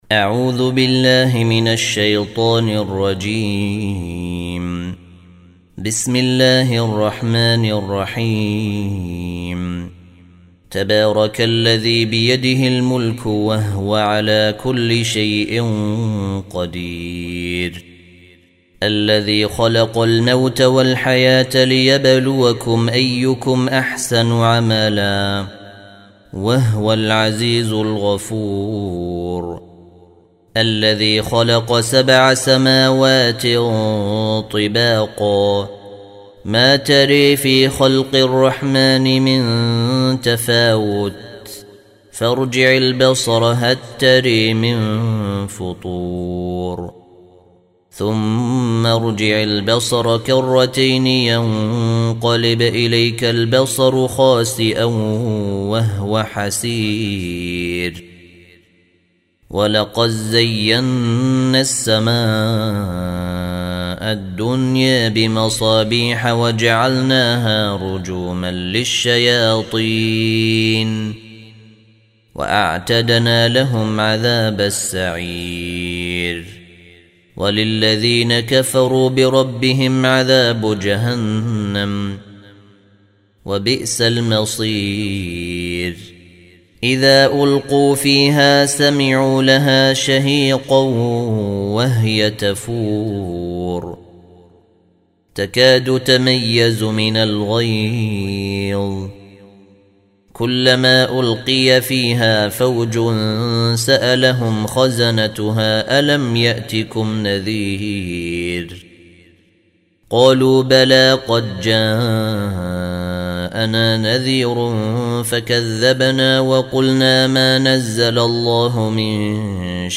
67. Surah Al-Mulk سورة الملك Audio Quran Tarteel Recitation
Surah Repeating تكرار السورة Download Surah حمّل السورة Reciting Murattalah Audio for 67.